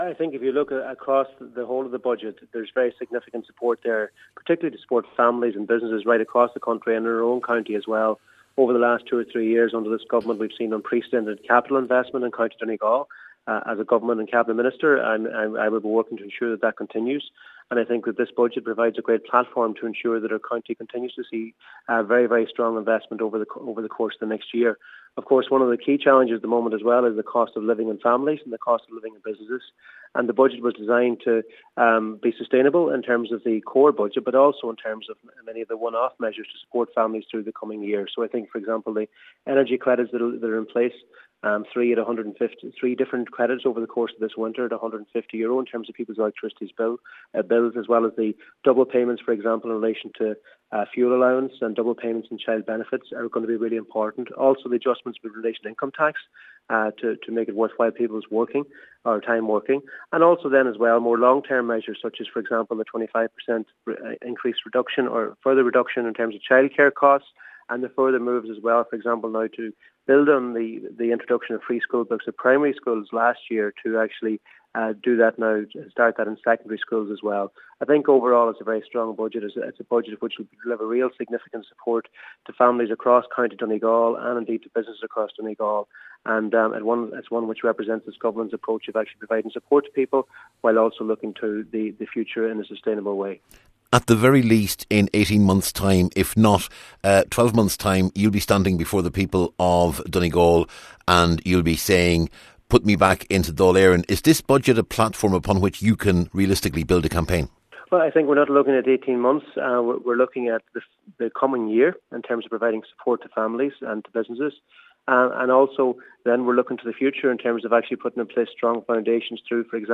Minister McConalogue told Highland Radio News that Budget 2024 needed to be prudent while also making meaningful interventions, and he believes it has succeeded..……..